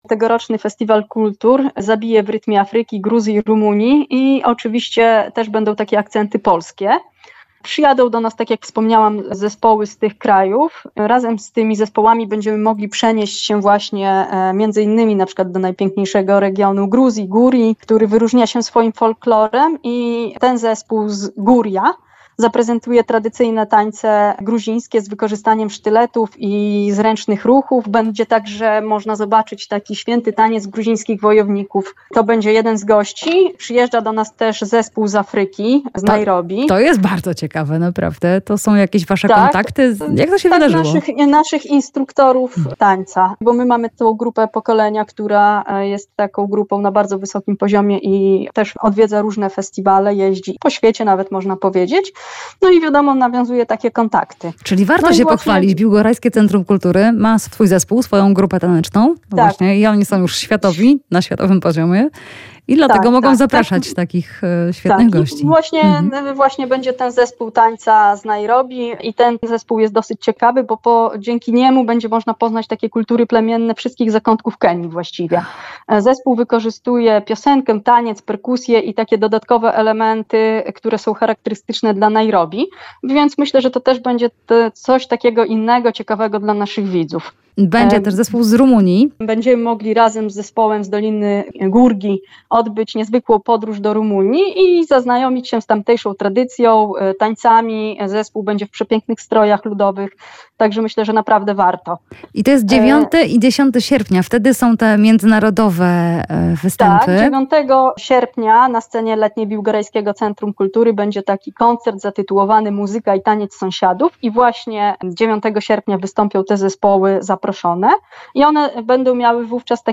Festiwal Kultur w Biłgoraju [POSŁUCHAJ ROZMOWY]